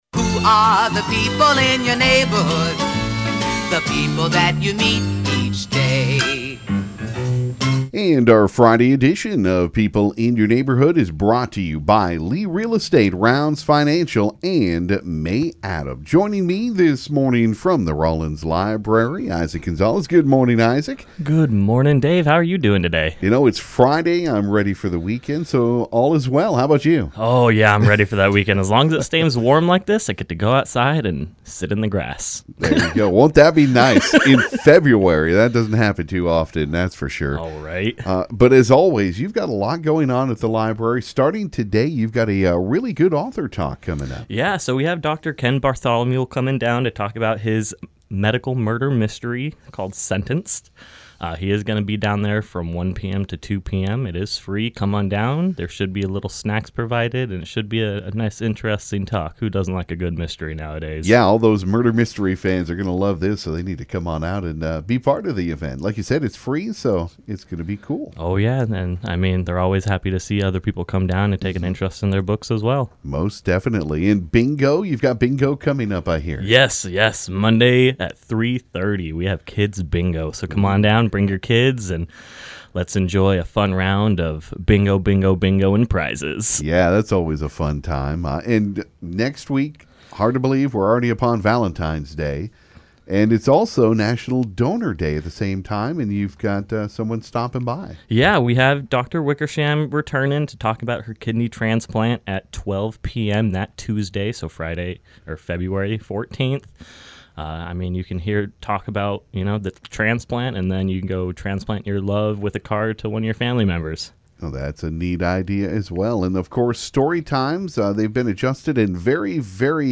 This morning on KGFX it was time for a visit from Rawlins Library for People In Your Neighborhood.